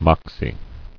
[mox·ie]